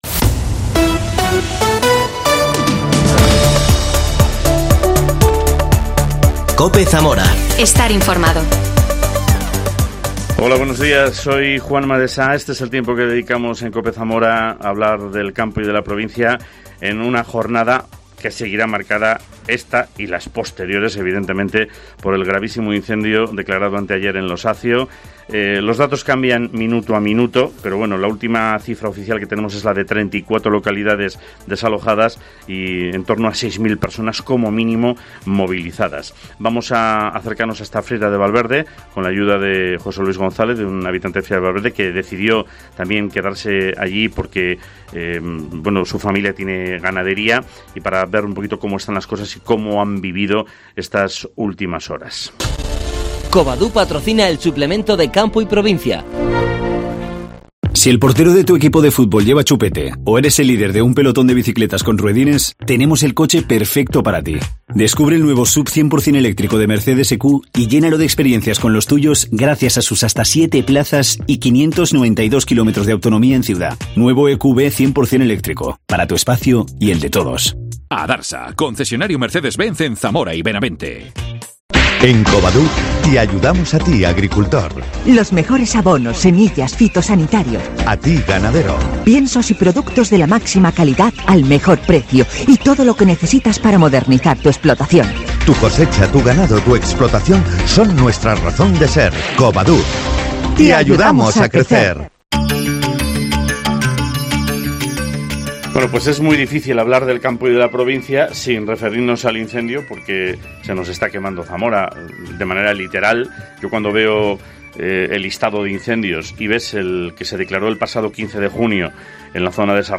AUDIO: Entrevista a un vecino de Friera de Valverde